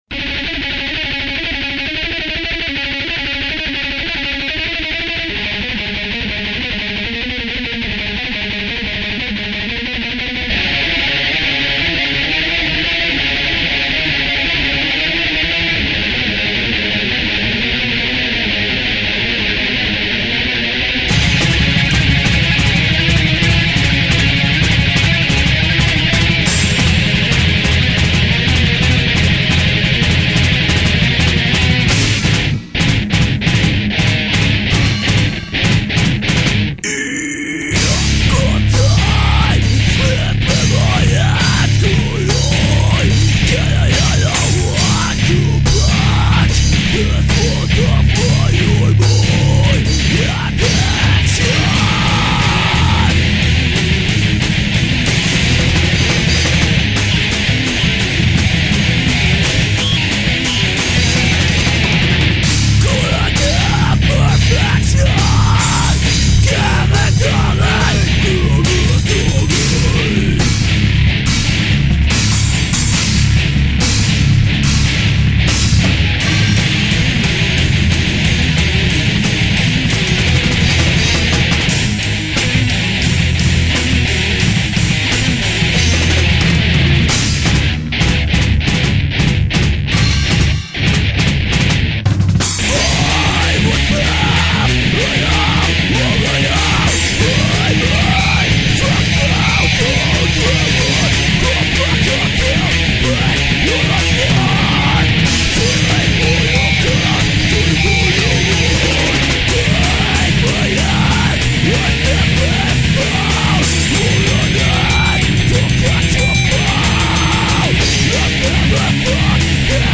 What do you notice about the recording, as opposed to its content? self recorded demo